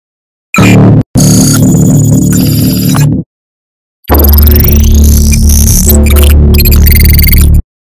HackingSound.ogg